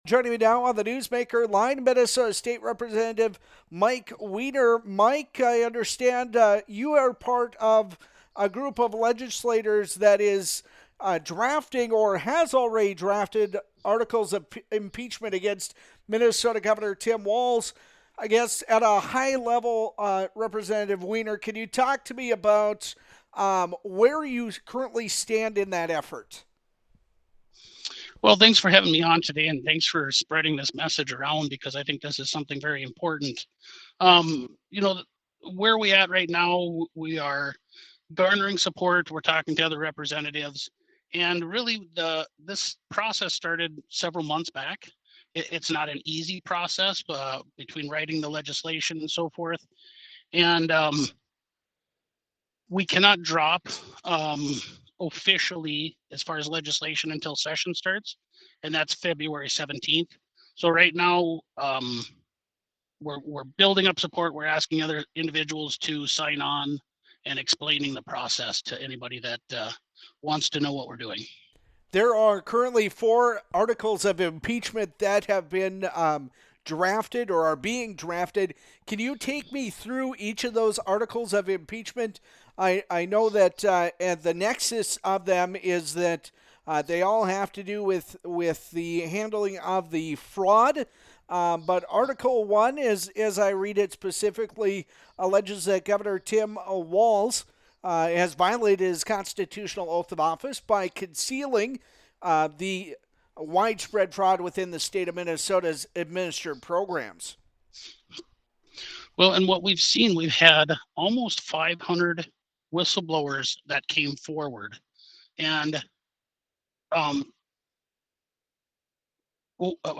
Minnesota State Representative Mike Wiener’s interview with Flag Family News